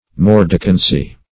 Mordicancy \Mor"di*can*cy\, n. A biting quality; corrosiveness.